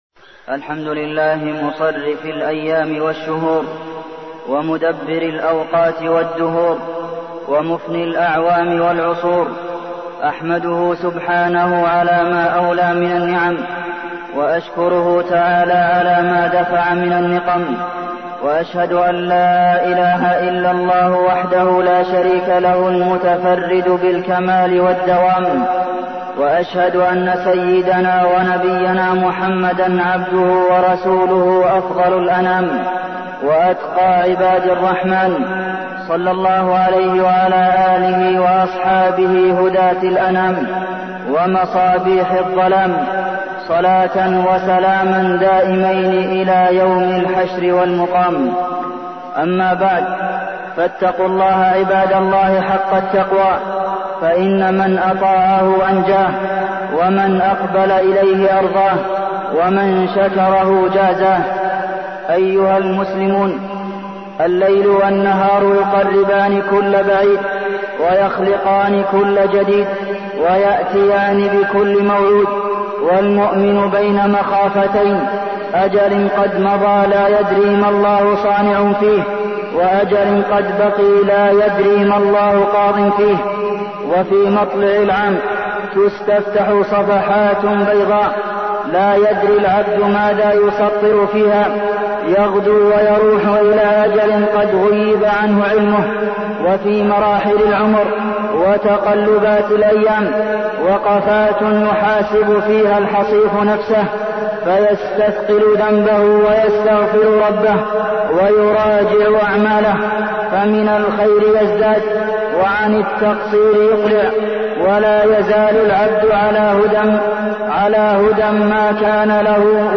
تاريخ النشر ٣٠ ذو الحجة ١٤١٩ هـ المكان: المسجد النبوي الشيخ: فضيلة الشيخ د. عبدالمحسن بن محمد القاسم فضيلة الشيخ د. عبدالمحسن بن محمد القاسم نهاية العام ومحاسبة النفس